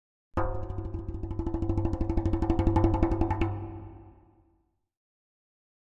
Drums Percussion Danger - Fast Drumming On A Thin Metal Percussion 4